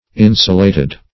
Insulated \In"su*la`ted\ ([i^]n"s[-u]*l[=a]"t[e^]d), p. a.